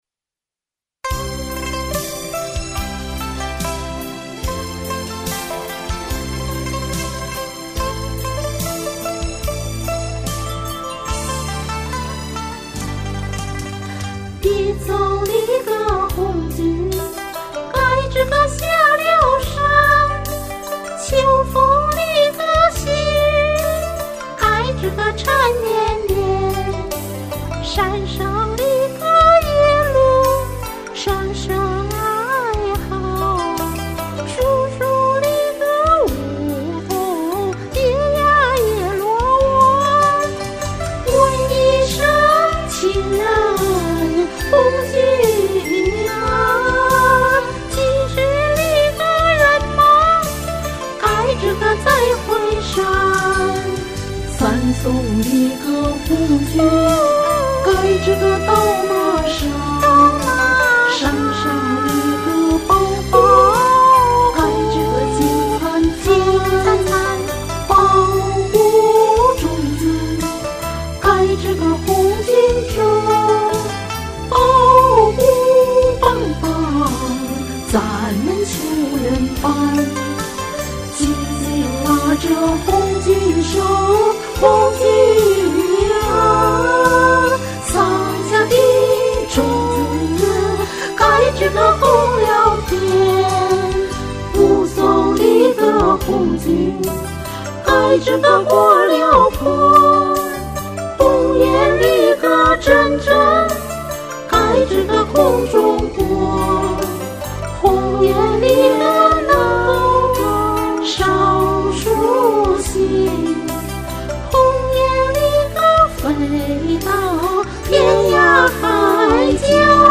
《十送红军》是一首江西民歌，红歌。
我手头有这首歌的伴奏，没上高山之前，我曾用耳麦唱录过这首歌，当时降了一个音。
我唱歌录音必须站着，不会坐着唱歌。
我安慰自己：能用原调唱下来，说明自己上山三年来，还是有所进步的，呵呵！